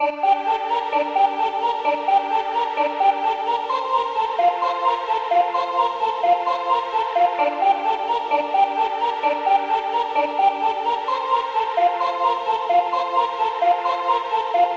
电子合唱团
Tag: 130 bpm Electronic Loops Choir Loops 2.49 MB wav Key : Unknown